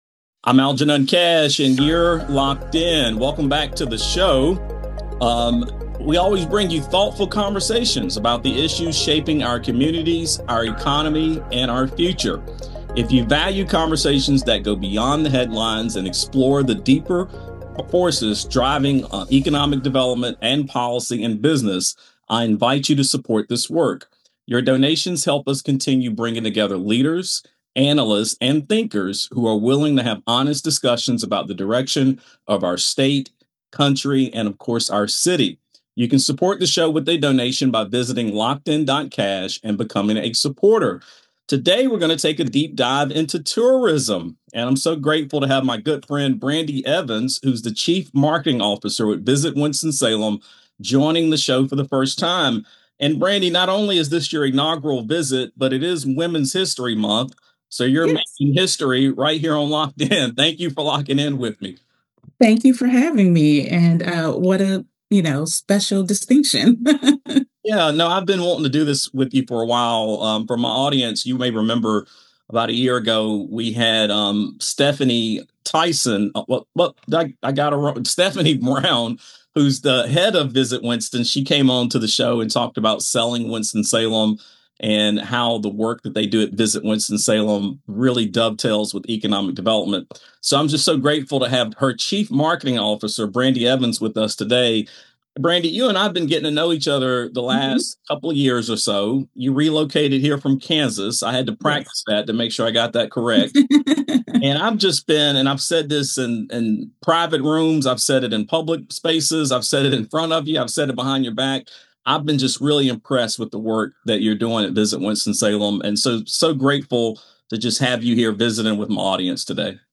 This conversation also highlights how tourism and economic development strategies intersect with real estate, small business growth, and talent attraction.